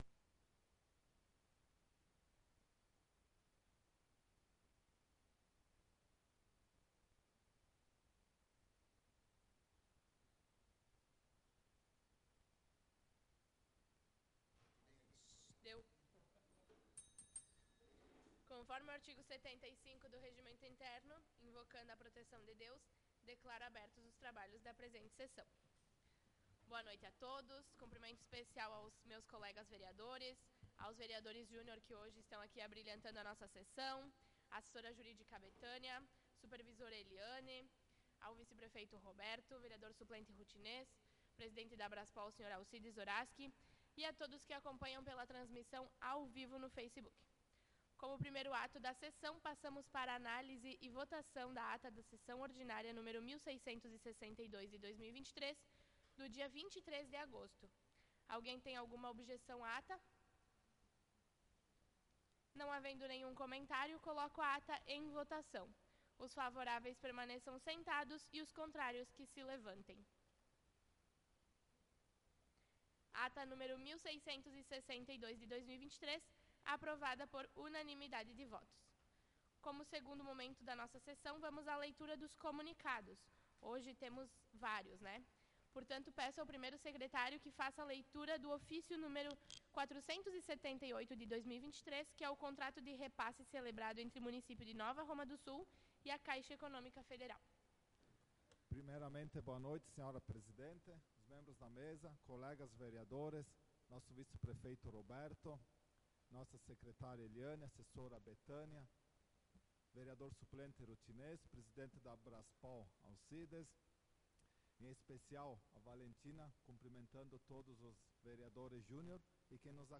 Sessão Ordinária do dia 30/08/2023 - Câmara de Vereadores de Nova Roma do Sul
Sessão Ordinária do dia 30/08/2023